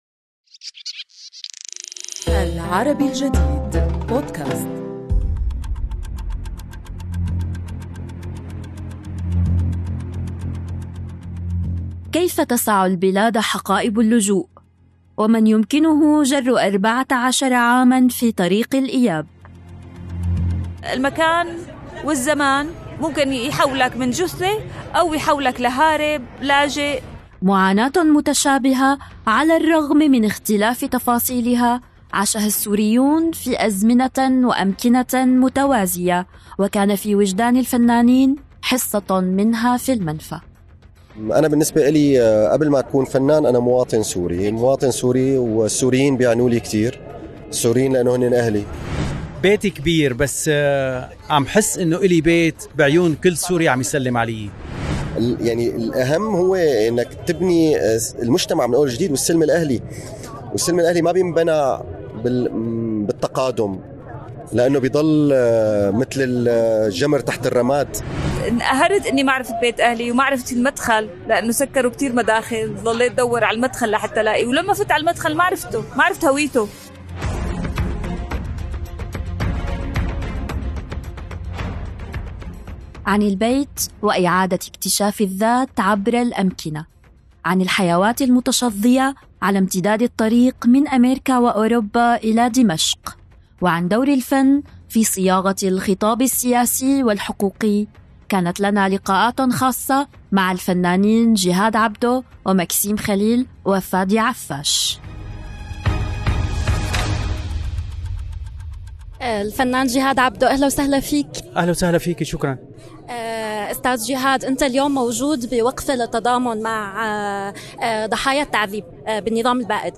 لقاء خاص